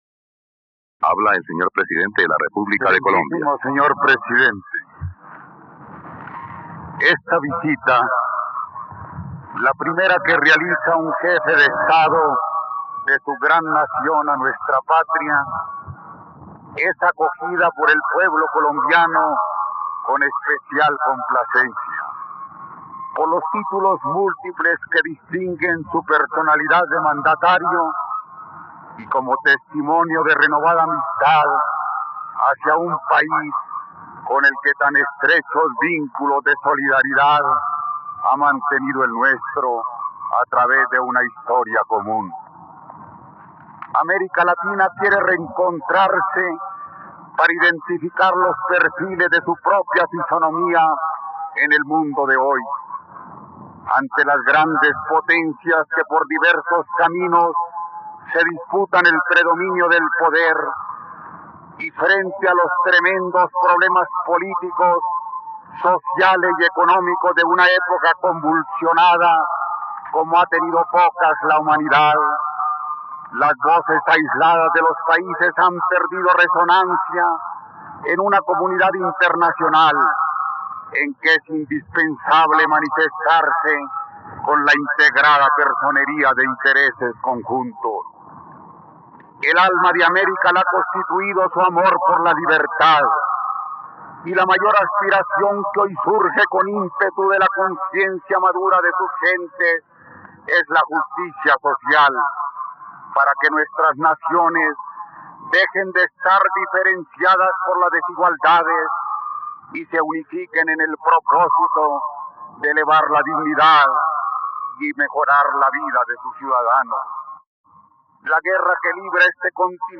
..Escucha ahora el discurso de Misael Pastrana Borrero durante la visita del mandatario argentino Alejandro Agustín Lanusse a Colombia en RTVCPlay.